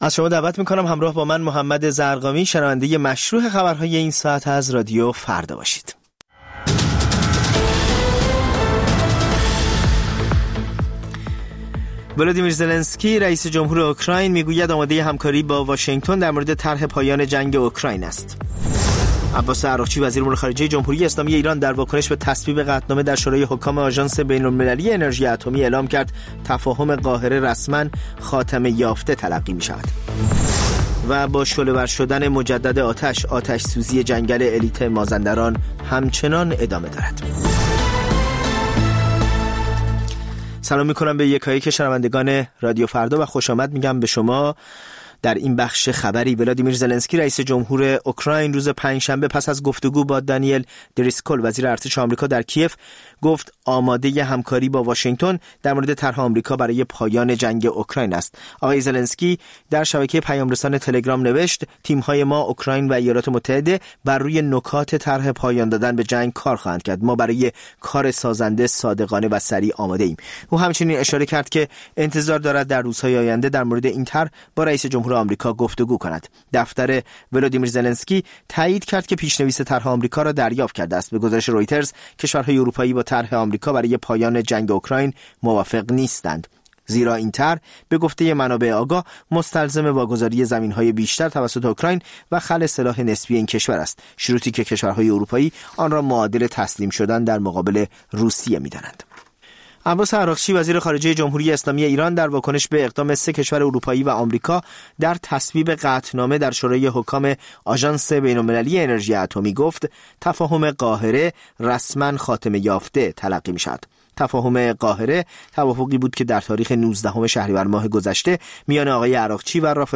سرخط خبرها ۸:۰۰